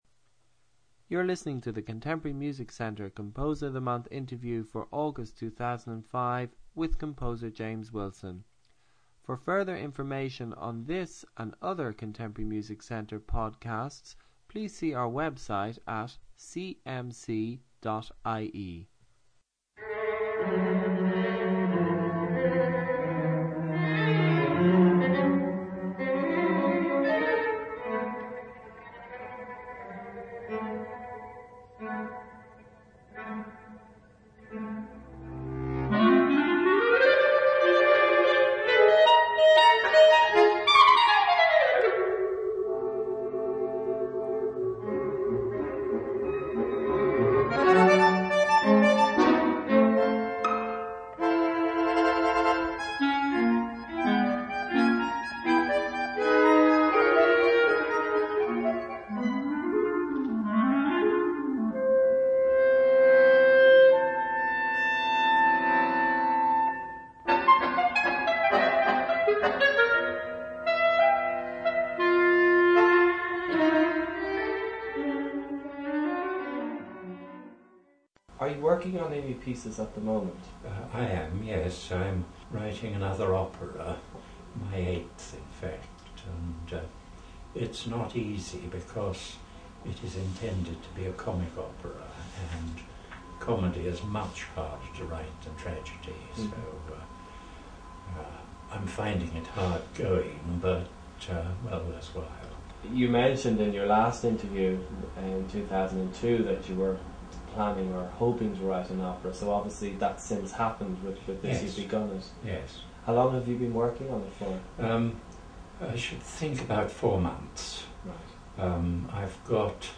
An Interview